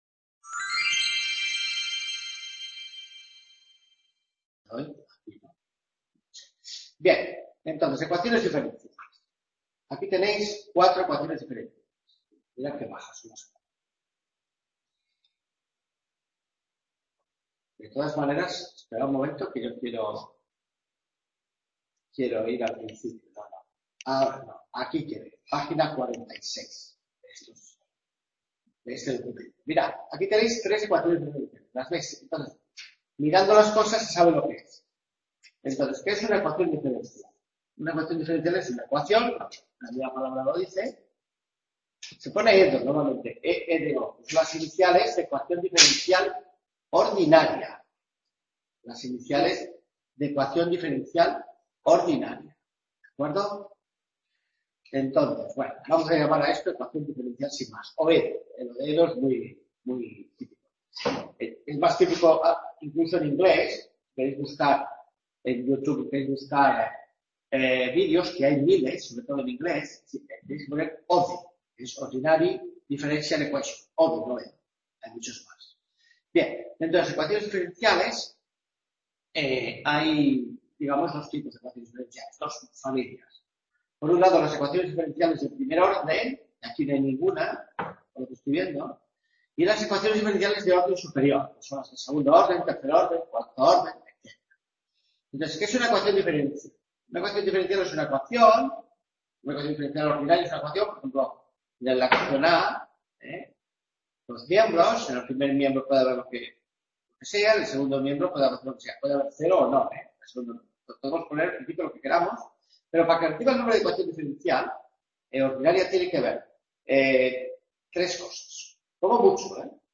Clase 4 mates 2 q2 1415 edos primer orden: separables…